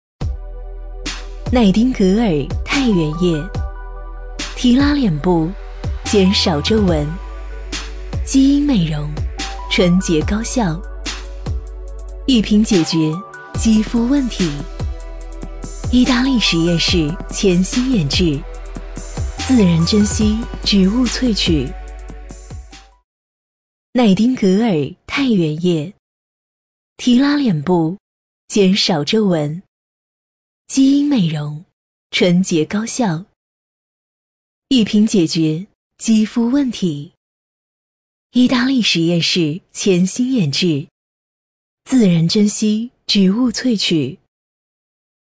【女50号广告】品质感-奈汀格尔
【女50号广告】品质感-奈汀格尔.mp3